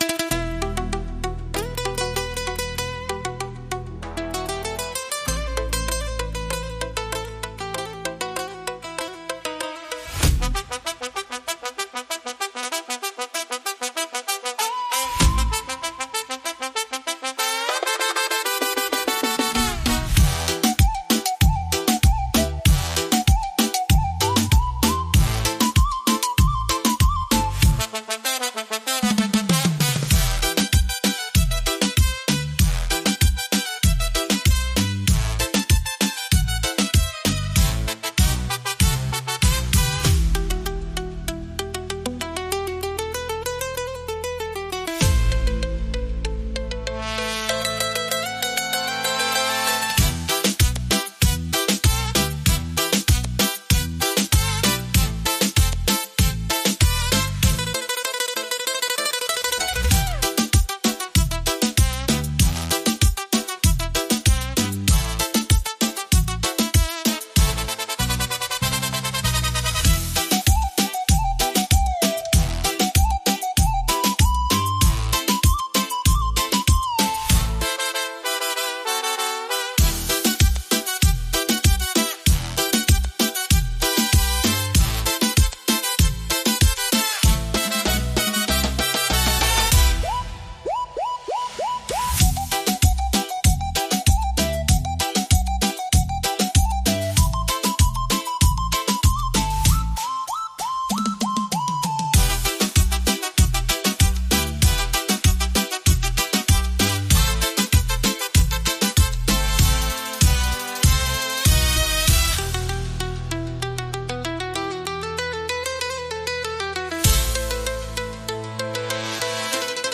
Beat Reggaeton Instrumental
Acapella e Cori Reggaeton Inclusi
• Mix e mastering di qualità studio
Em